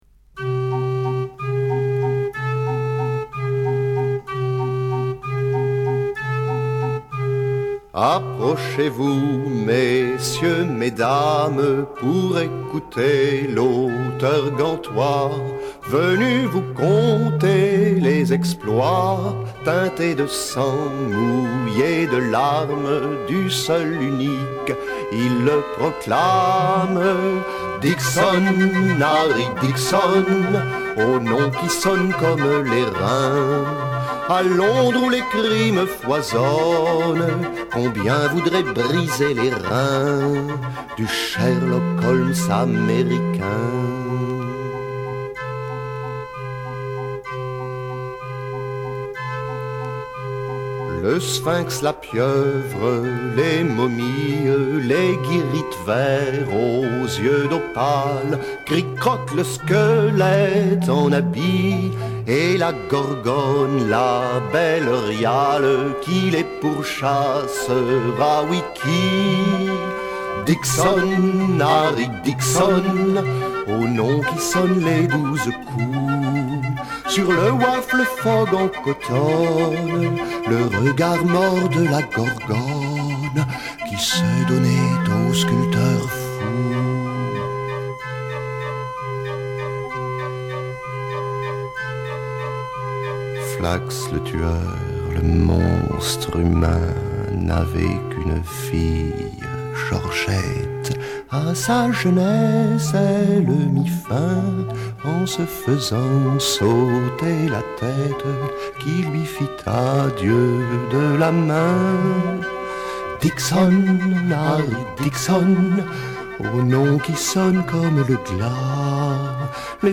Drame musical avec orgues de barbarie